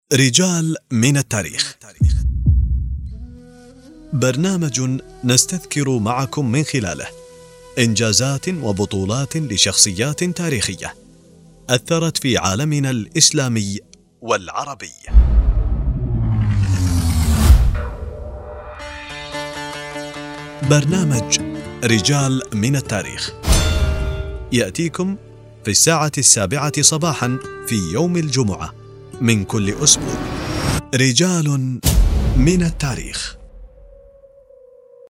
فلاش تقديمي لبرنامج اذاعي عنوانه (رجال من التاريخ) تم تقديمه عبر البرنامج الثاني في اذاعة دولة الكويت
فلاش برنامج اذاعي (رجال من التاريخ)